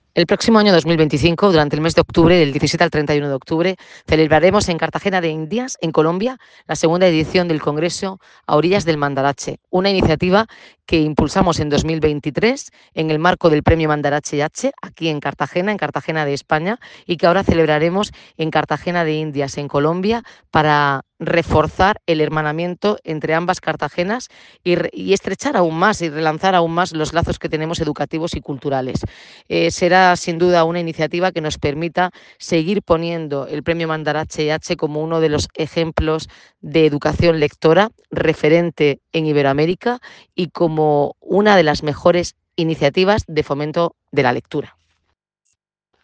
Enlace a Declaraciones de la alcaldesa, Noelia Arroyo, sobre el Congreso Mandarache en Colombia